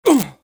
Grunts (as Punched) Male
Grunts (as Punched)  Male.wav